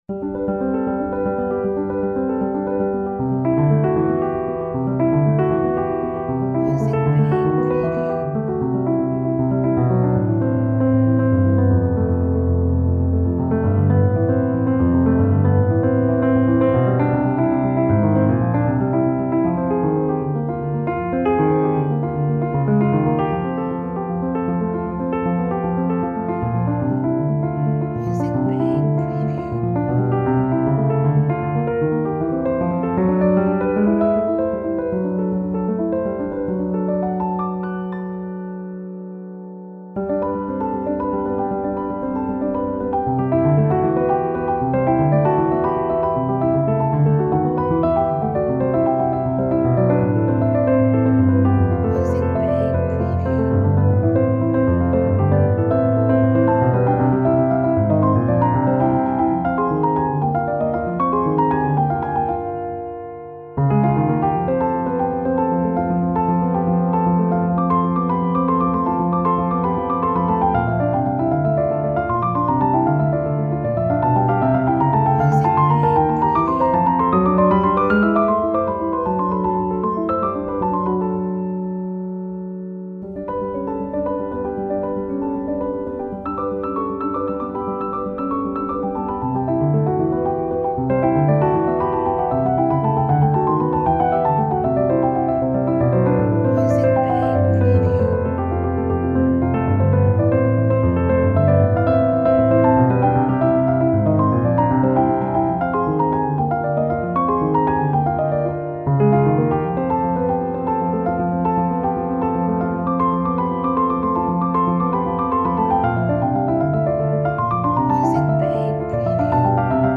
Inspiring music for video and advertising.